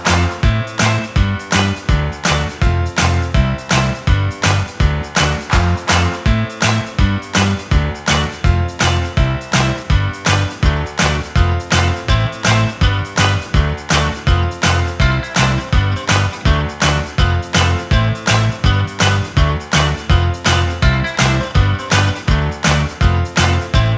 Minus Main Guitar Pop (1990s) 3:24 Buy £1.50